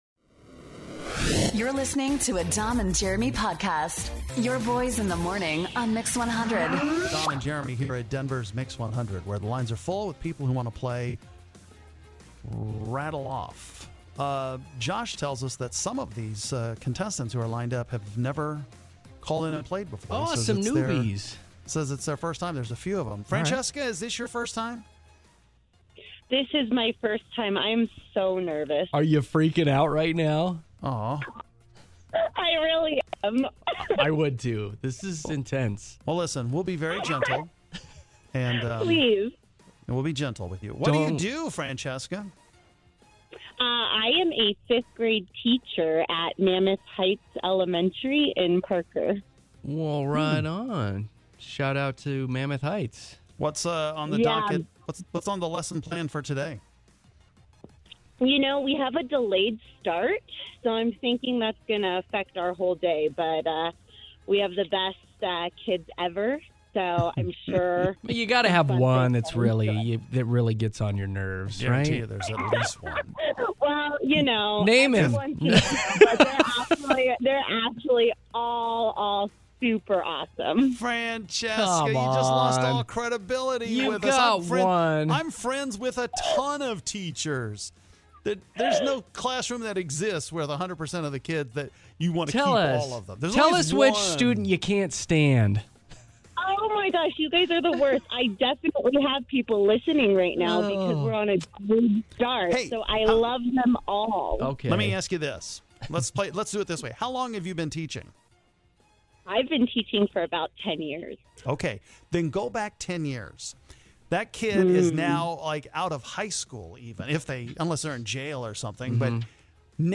We have a lot of listener's first times calling in today! Find out how they did playing Rattle Off.